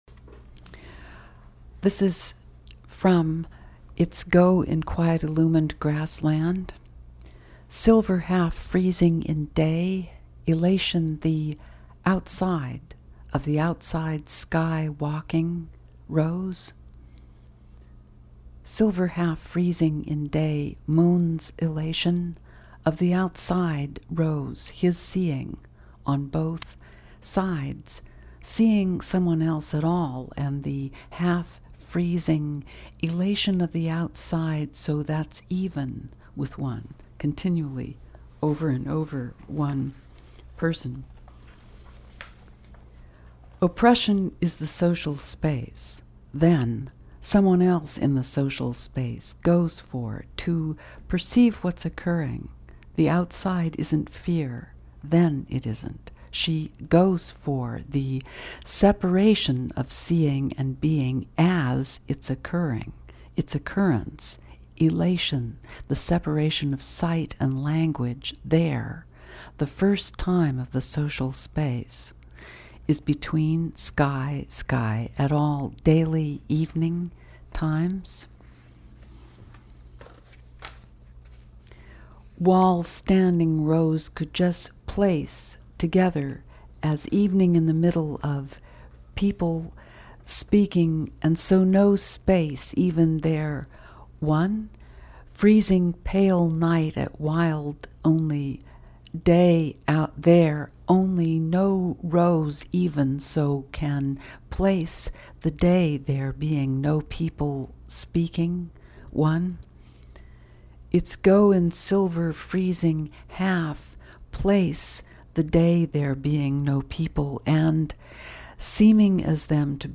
Leslie Scalapino reading excerpts from It's go in / quiet illumined grass / land